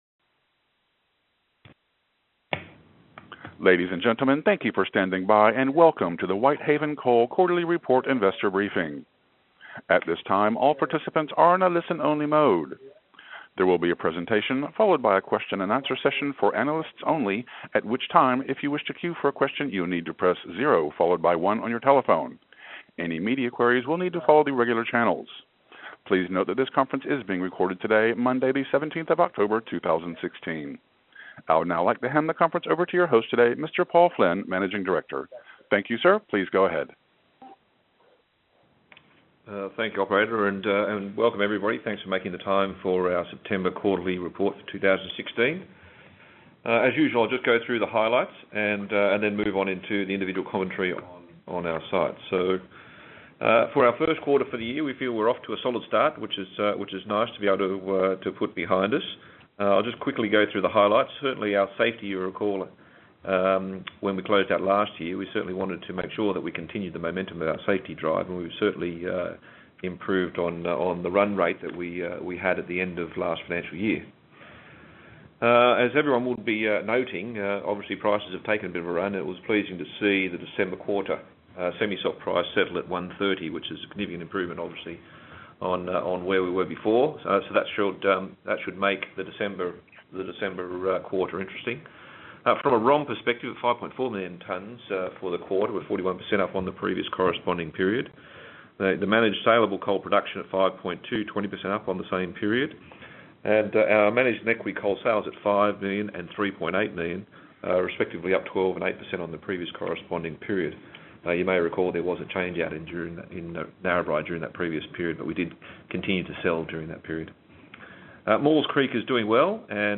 The audio of the Investor Briefing call can be found below: